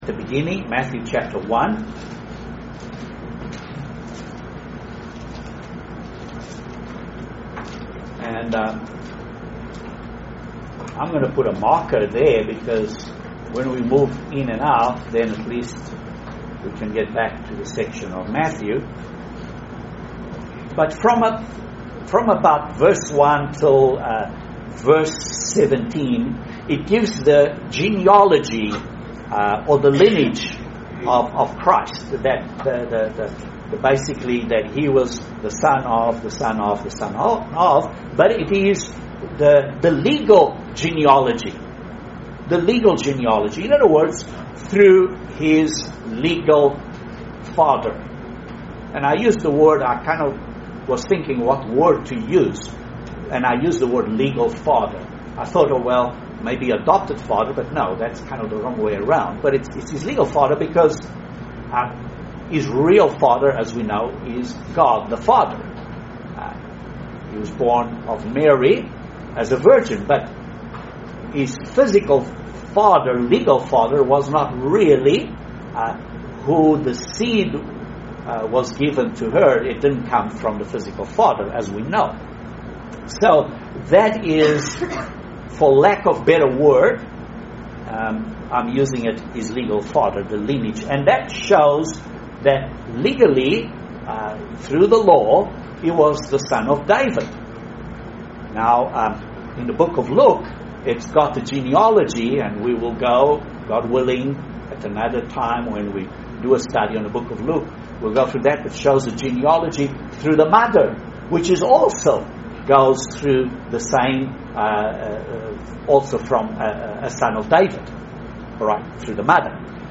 Great Bible study of the first part of the book of Matthew.